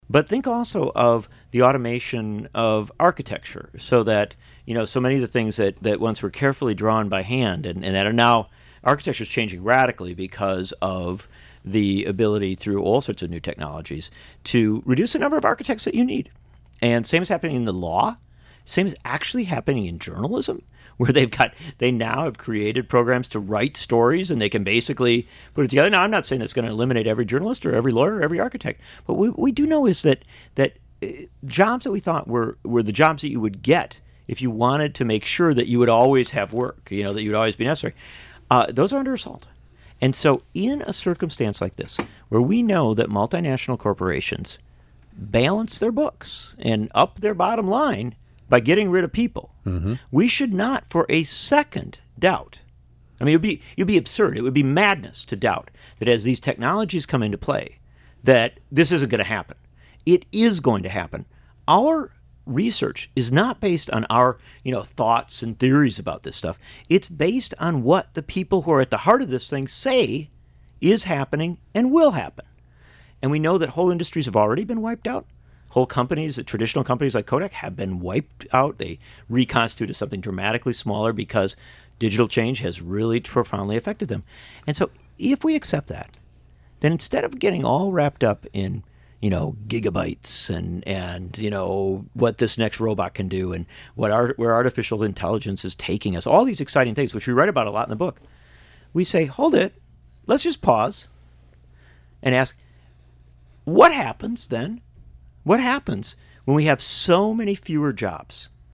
In-Depth Interview, Pt. II: Nichols and McChesney Talk About the Future, and How to Make it More Democratic